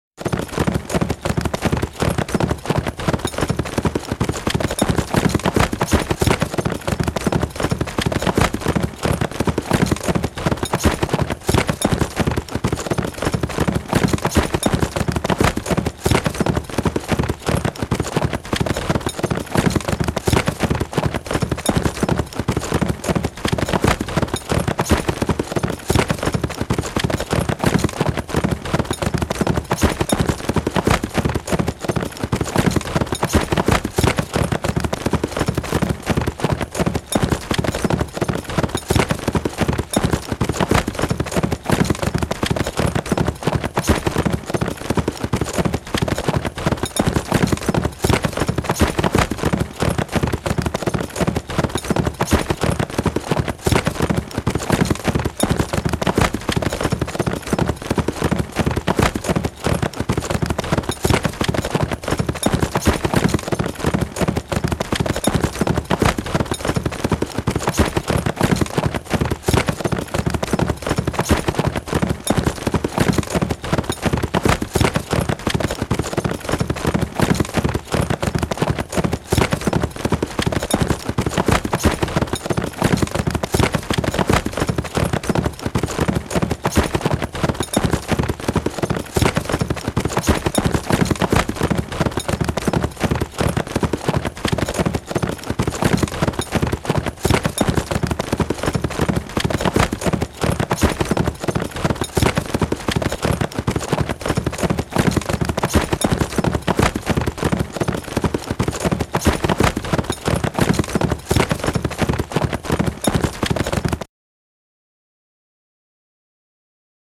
جلوه های صوتی
دانلود صدای اسب 5 از ساعد نیوز با لینک مستقیم و کیفیت بالا
برچسب: دانلود آهنگ های افکت صوتی انسان و موجودات زنده دانلود آلبوم انواع صدای شیهه اسب از افکت صوتی انسان و موجودات زنده